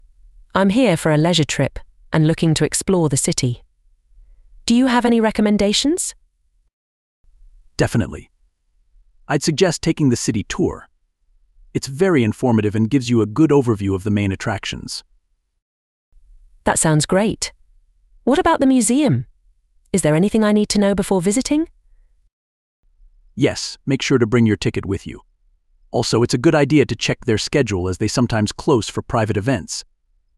You will hear three conversations.